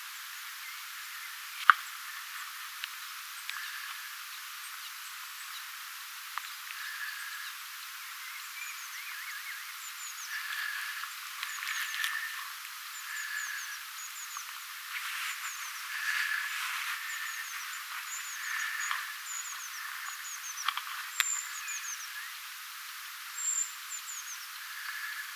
ensimmäinen keltanokkarastaslinnun laulu
keltanokkarastaslintu_laulaa.mp3